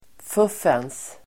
Uttal: [f'uf:ens]